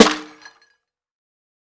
HFMSnare7.wav